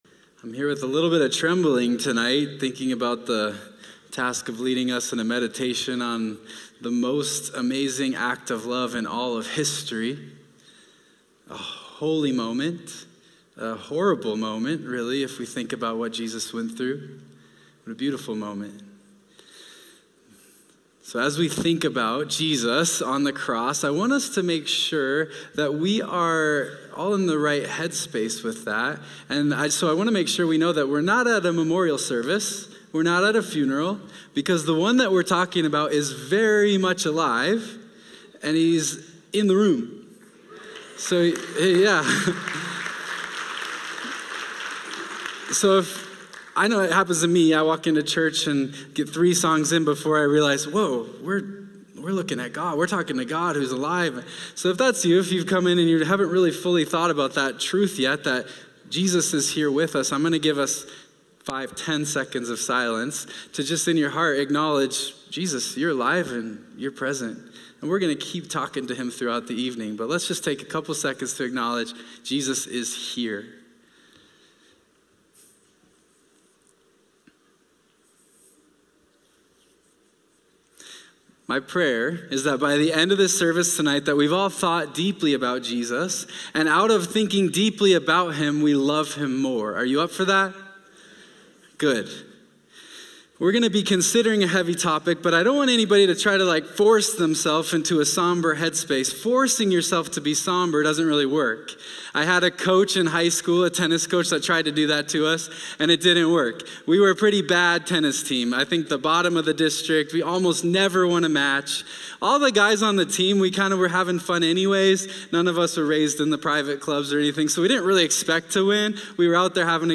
In this Good Friday meditation, we enter into the powerful story of Jesus’ crucifixion, not as mourners at a funeral, but as worshipers who know the ending. We reflect on what Jesus endured, how He thought of others even in His suffering, and how He fulfilled ancient prophecies to open the way of salvation for us all.